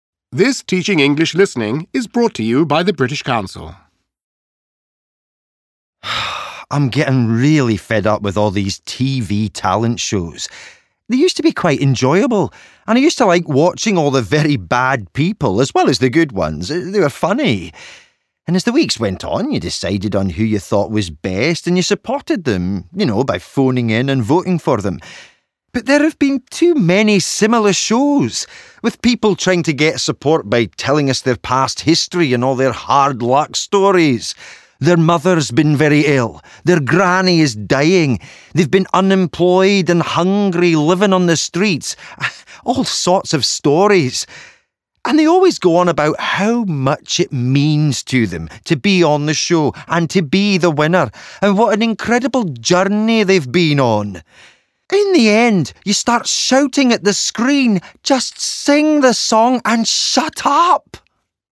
About the audioIn this recording, students listen to a man from Scotland complaining about the amount of talent shows there are on television at the moment.